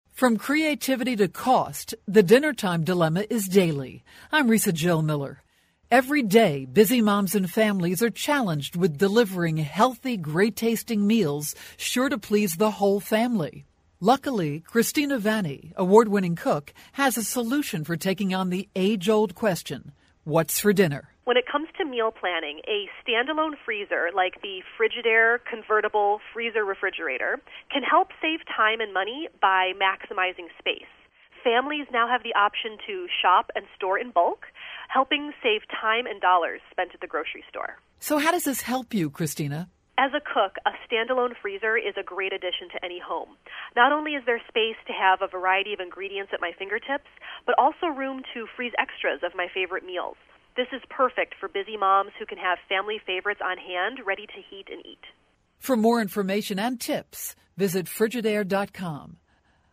February 21, 2013Posted in: Audio News Release